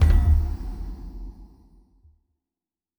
Fantasy Interface Sounds
Special Click 10.wav